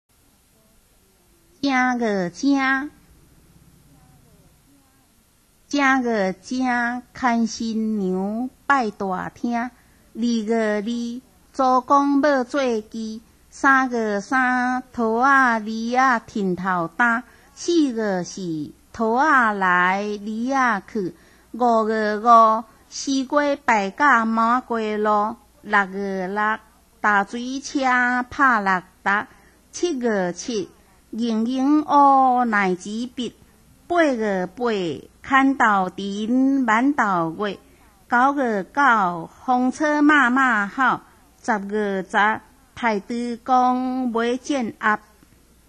正月正()  聽聽看~台語發音